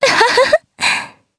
Lewsia_B-Vox_Happy2_jp.wav